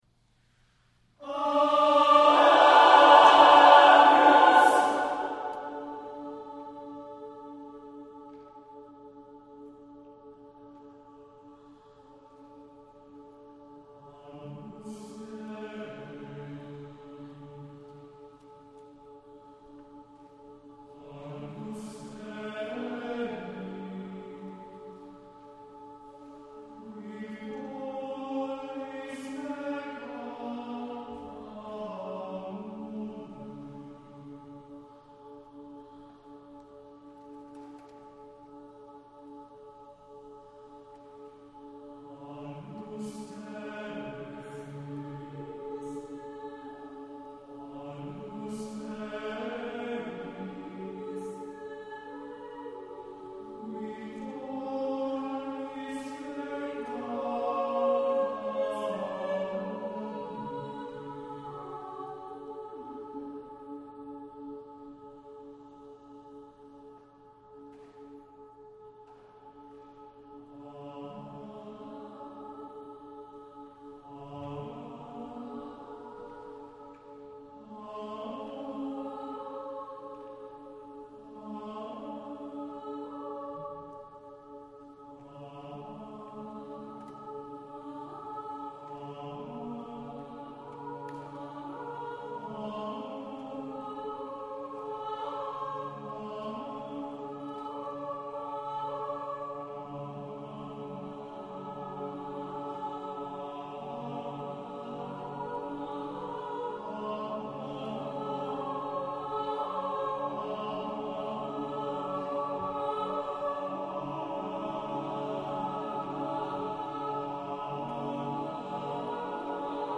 Voicing: SSATBB divisi